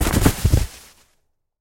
Звуки снеговика
Хруст ломающегося снега